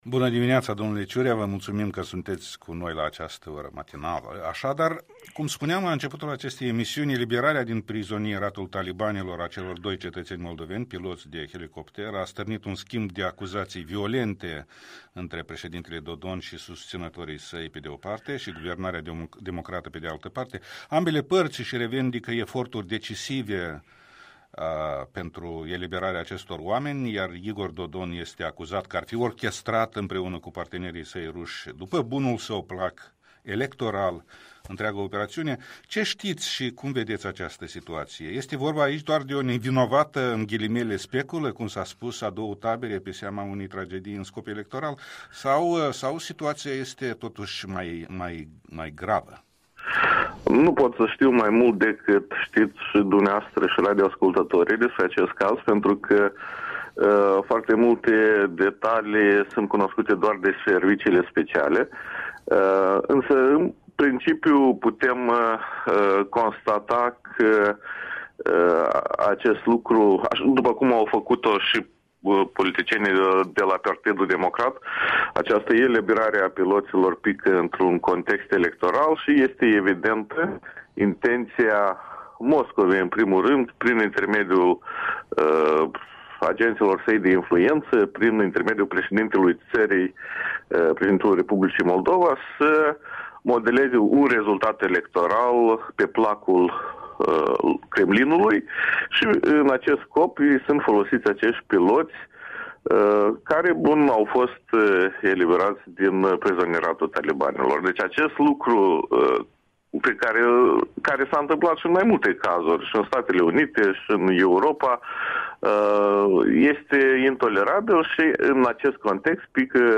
Interviul matinal al Europei Libere despre disputele președintelui Igor Dodon cu guvernarea democrată în cazul eliberării piloţilor ţinuţi ostatici în Afganistan.